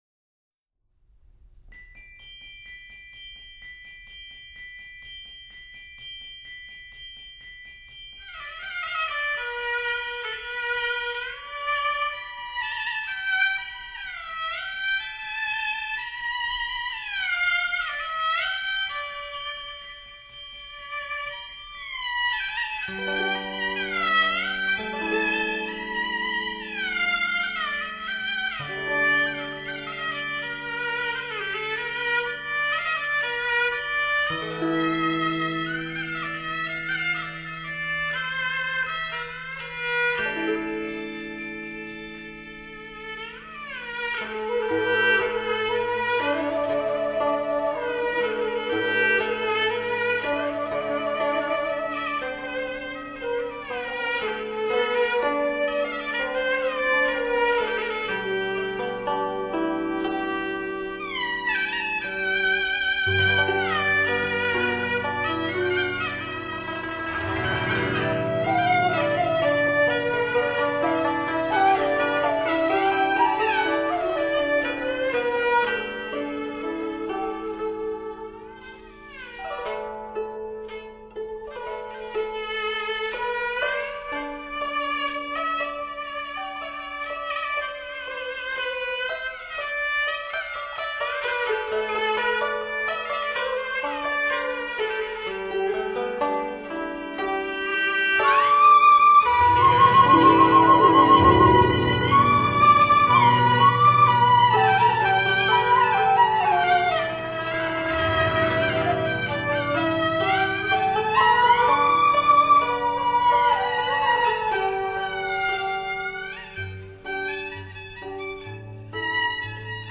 【答谢师兄】民族音乐 中樂之空前製作
以中樂為體，配以西樂編曲之架構，大師們高超的即興演奏，讓人耳目一新。